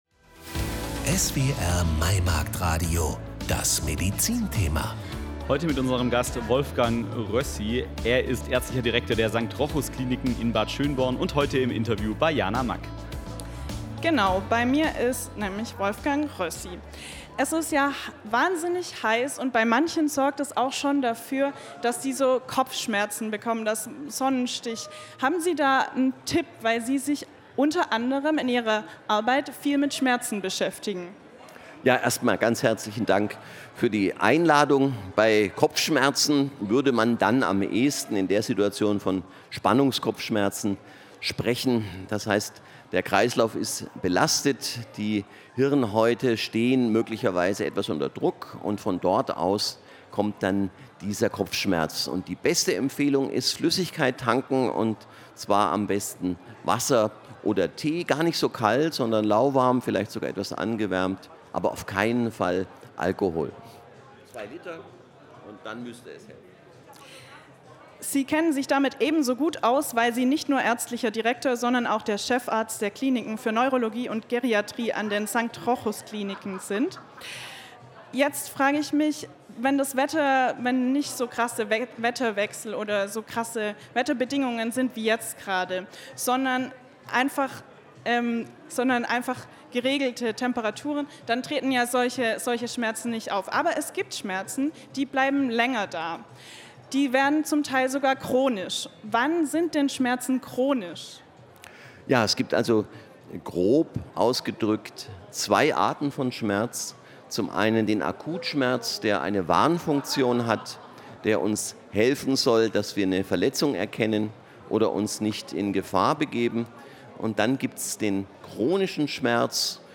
12.06.2025 Gläsernes Studio des SWR auf dem Maimarkt 2025
Interview